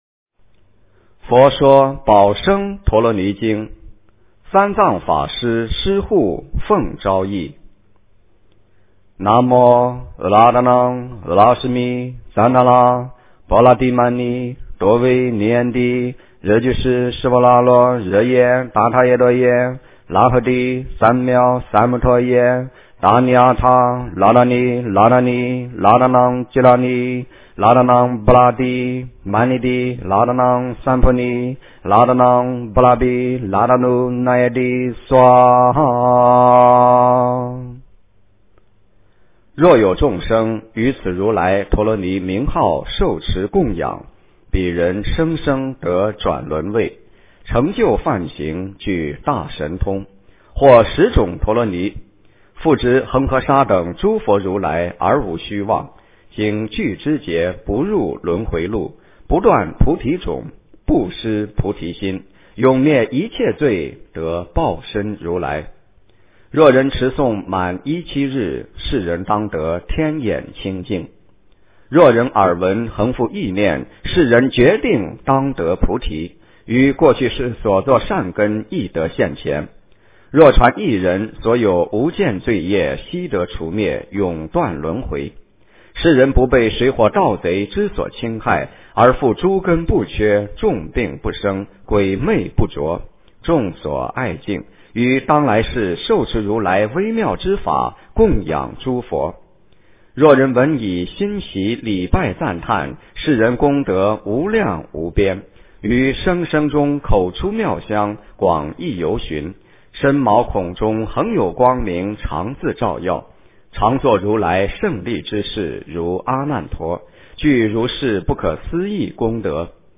佛说宝生陀罗尼经 - 诵经 - 云佛论坛